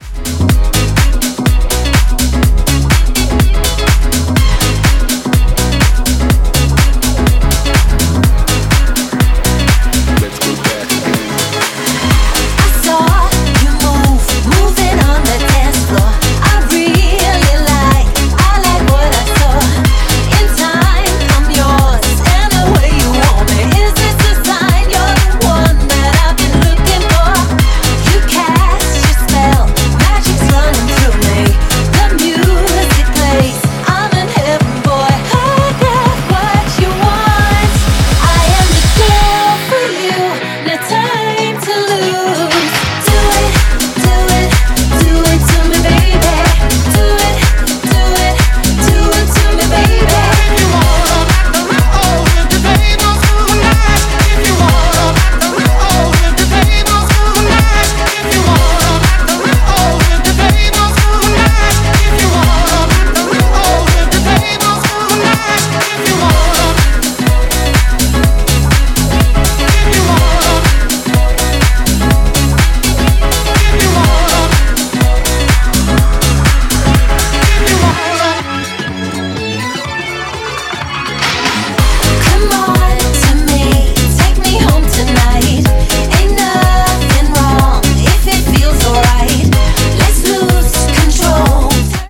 > HOUSE・TECHNO
ジャンル(スタイル) HOUSE / NU DISCO / DISCO